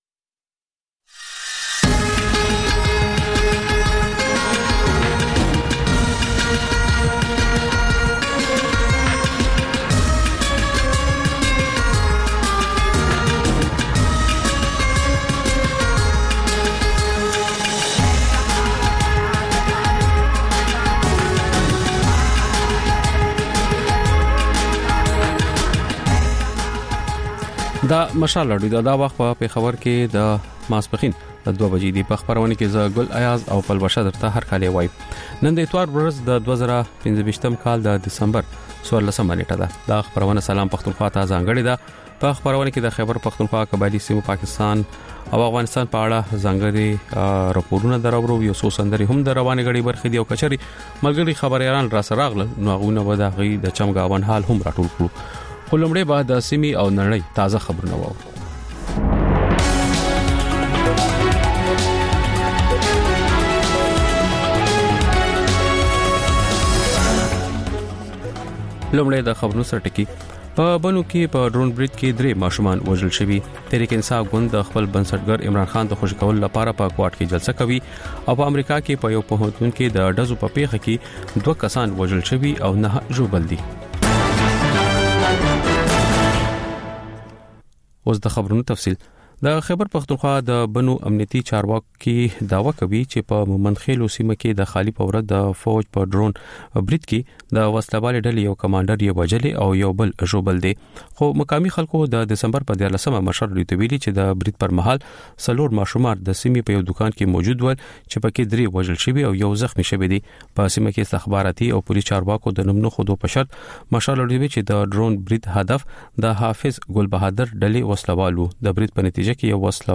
د مشال راډیو دویمه ماسپښینۍ خپرونه. په دې خپرونه کې لومړی خبرونه او بیا ځانګړې خپرونې خپرېږي.